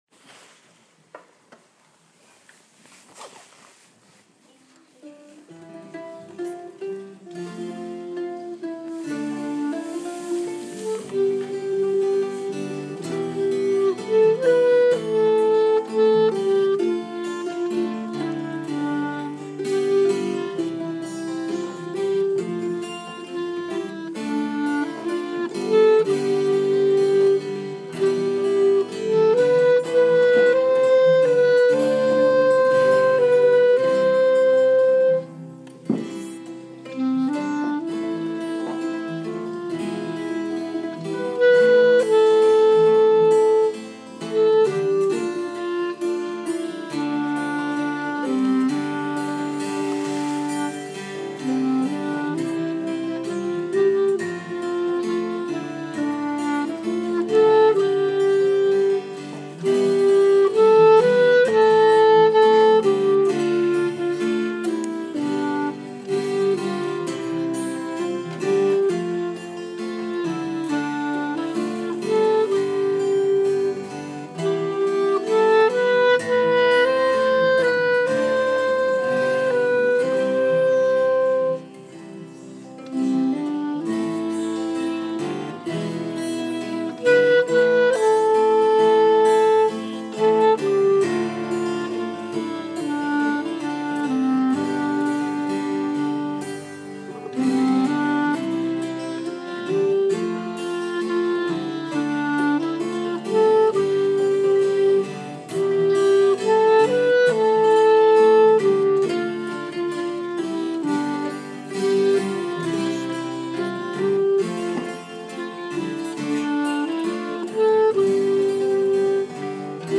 sunday instrumental